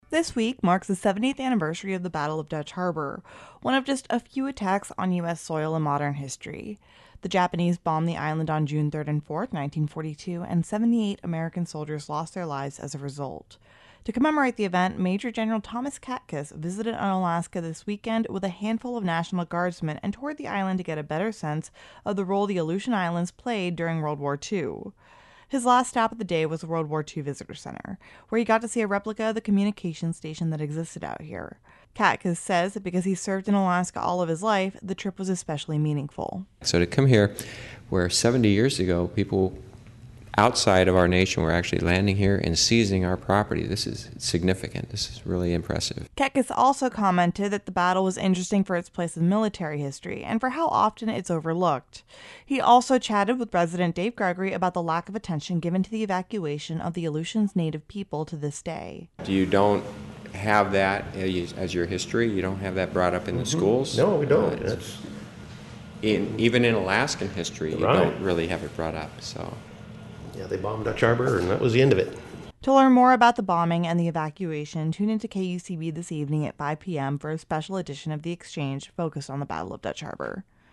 Major General Thomas Katkus speaks at the Museum of the Aleutians about Unalaska's role in World War II.